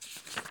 x_enchanting_scroll.4.ogg